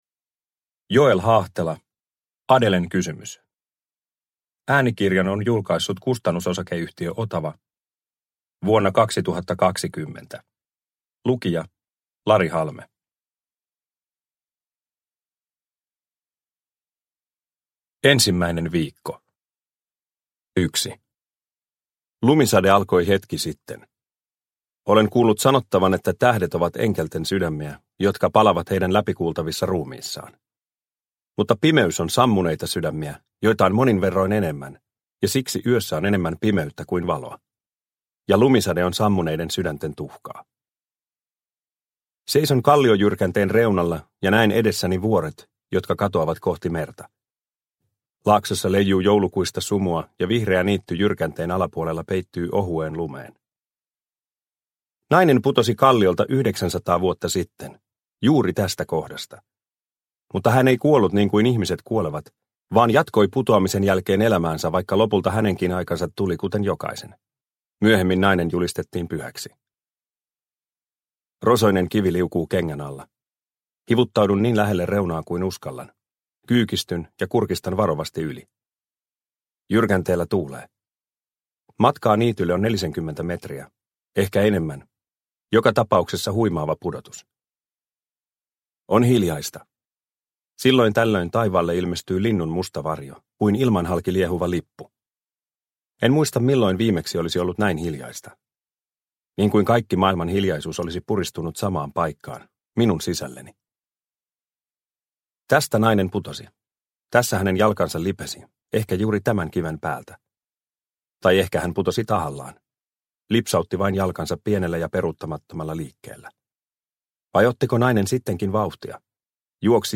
Adèlen kysymys – Ljudbok – Laddas ner